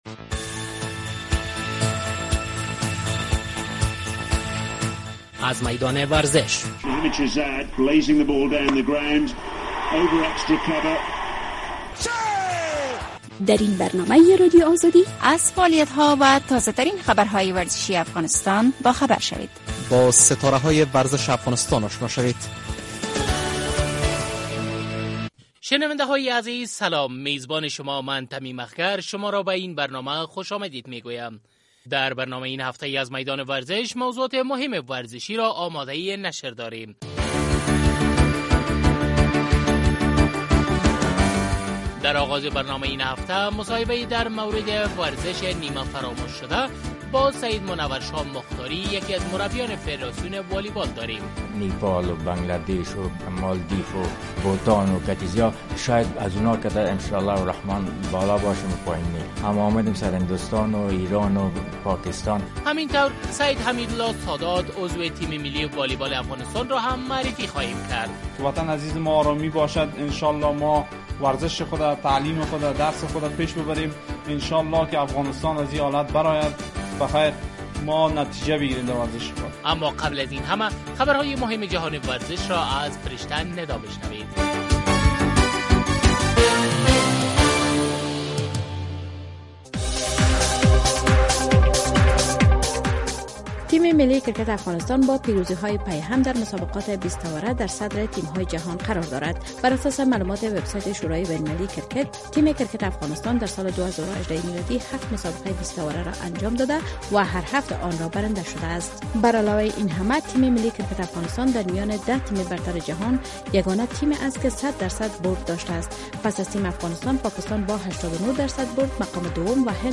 در برنامه این هفته از میدان ورزش موضوعات مهم ورزشی آماده شده‌است. در آغاز برنامه این هفته مصاحبه در مورد ورزش ...